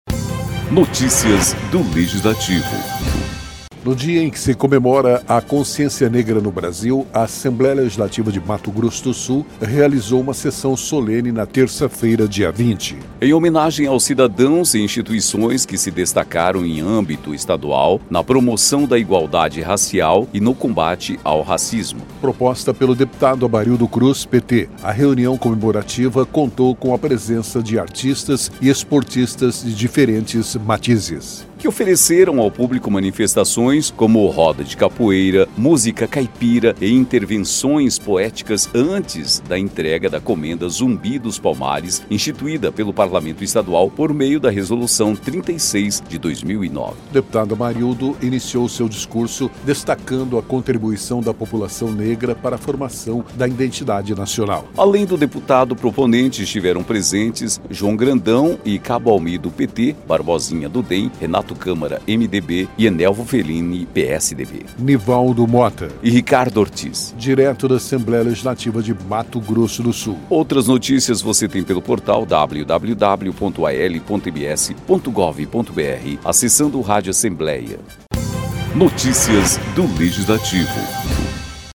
No dia em que se comemora a Consciência Negra no Brasil, a Assembleia Legislativa de Mato Grosso do Sul realizou sessão solene nesta terça-feira (20) em homenagem aos cidadãos e instituições que se destacaram em âmbito estadual na promoção da igualdade racial e no combate ao racismo.